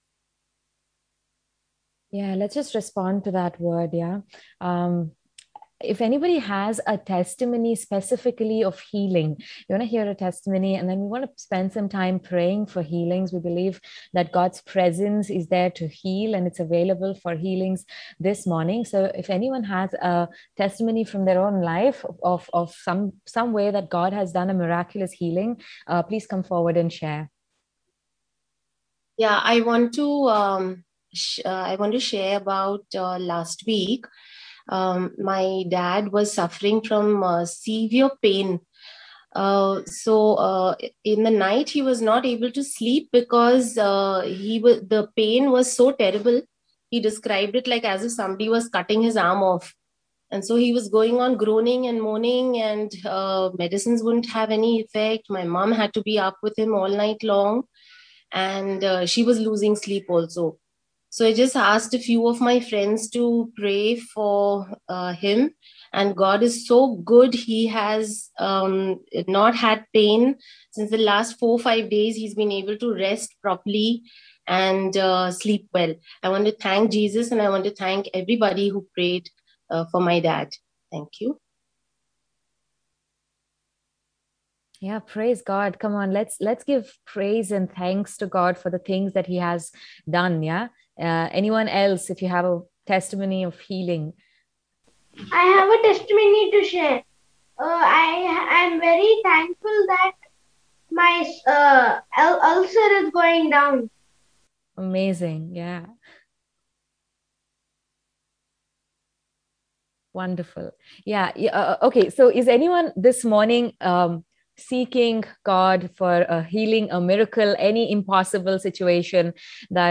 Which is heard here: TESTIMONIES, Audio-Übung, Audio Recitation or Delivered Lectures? TESTIMONIES